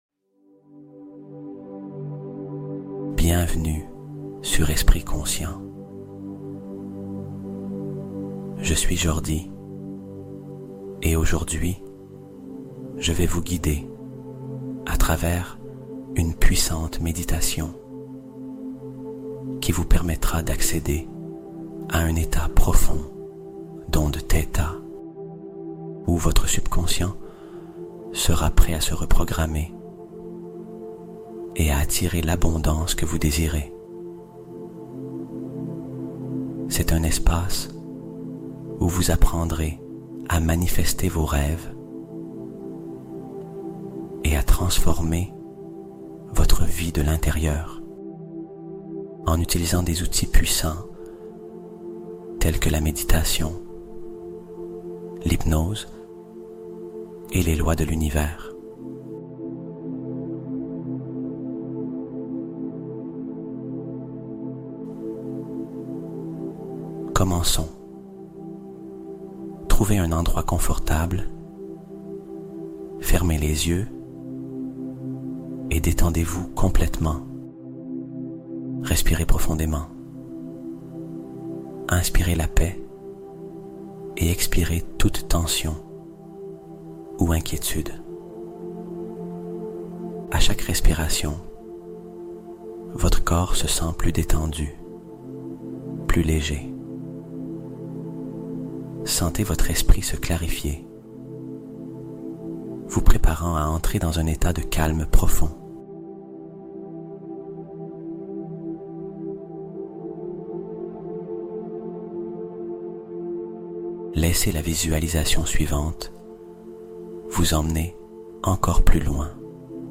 Ondes Theta : S'aligner avec la fréquence de l'abondance naturelle